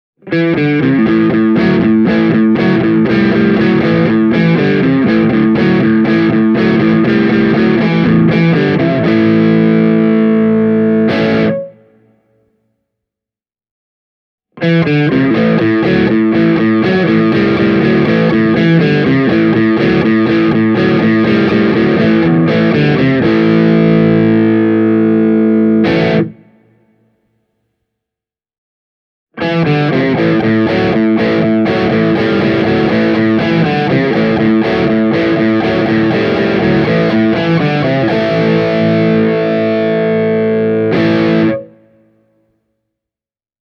Testasin Carvin Legacy 3 -nupin vaahterakaulaisella Fender Stratocasterilla ja Hamer USA Studio Custom -kitaralla Zilla Cabsin 2 x 12” -kaapin kautta, ja tulokset puhuvat hyvin selkeää kieltä – tässä on kyseessä pro-luokan vahvistin.
Hamer Studio Custom – kanava 2